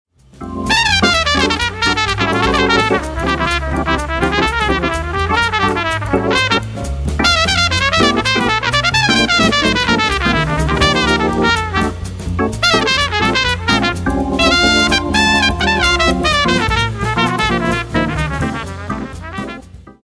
All FAT HEAD SAMPLES ARE RECORDED WITH A  STOCK TRANSFORMER
FAT HEAD Horn Samples
Trumpet:
FAT_HEAD_Trumpet.mp3